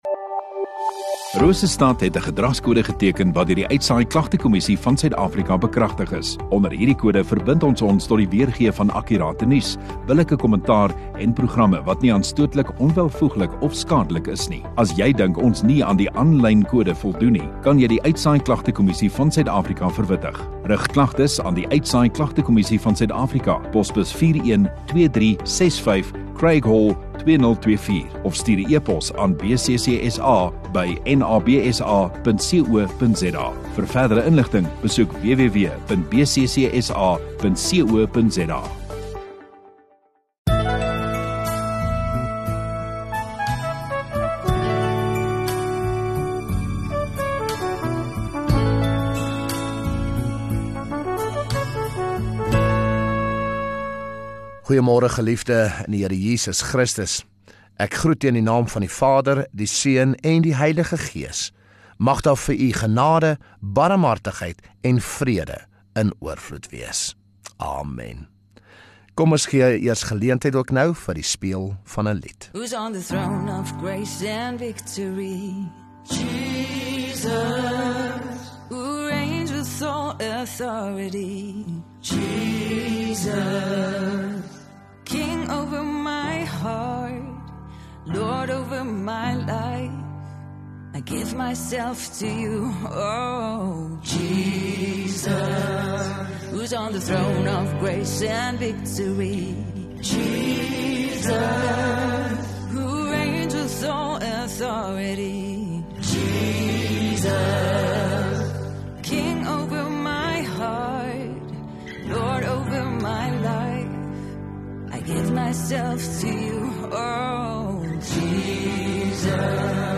20 Jul Sondagoggend Erediens